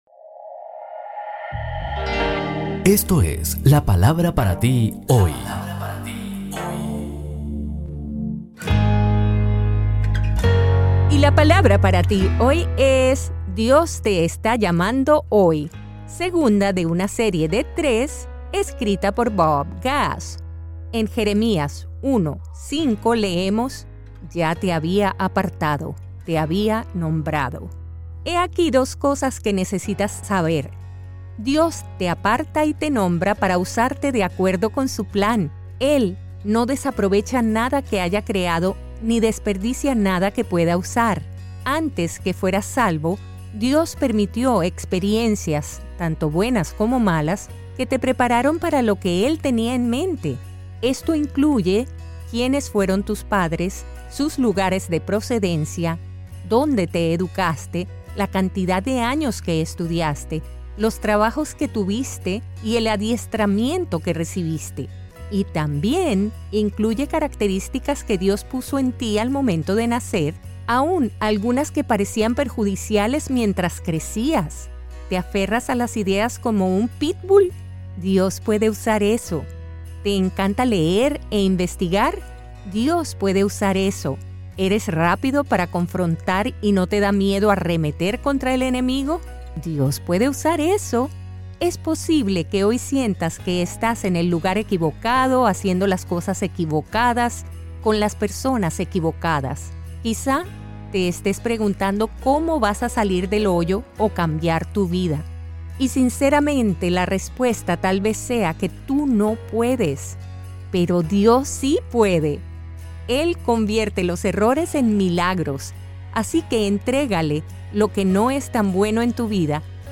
Segunda de esta magnífica serie de los devocionales de Bob Gass con la dulce voz de Elluz Peraza.